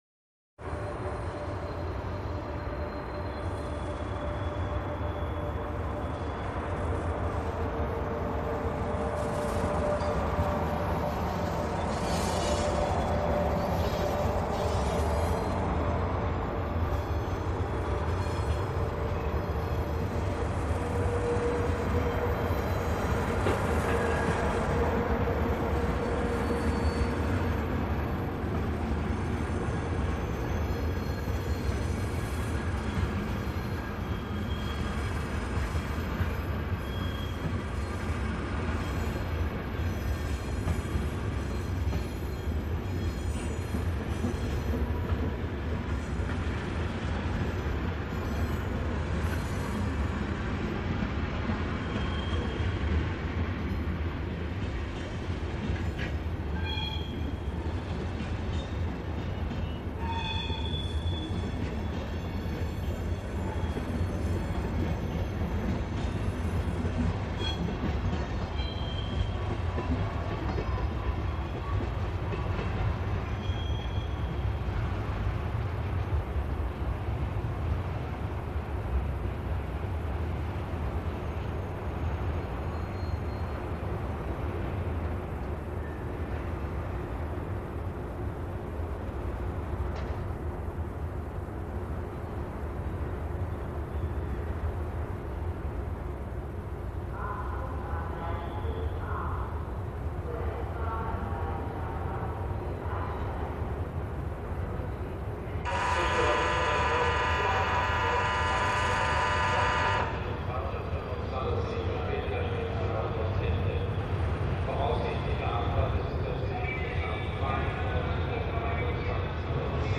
поезда звуки скачать, слушать онлайн ✔в хорошем качестве